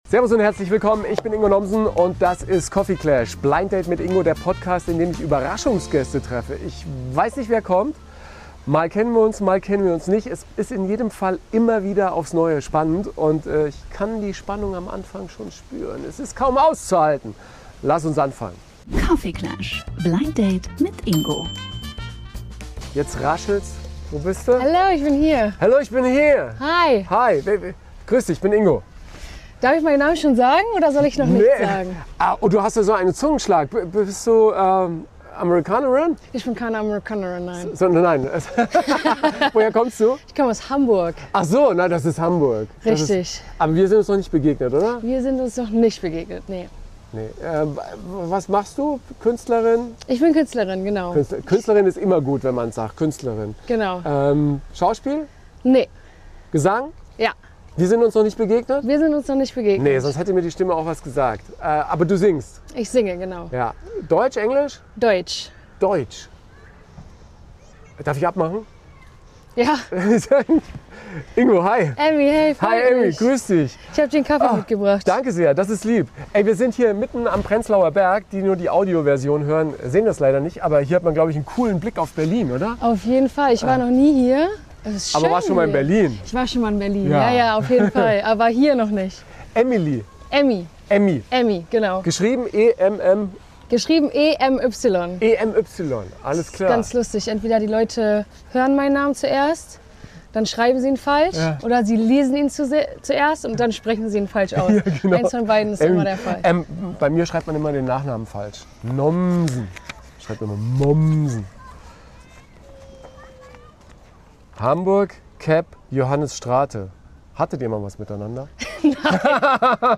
Es wird laut, emotional – und überraschend musikalisch.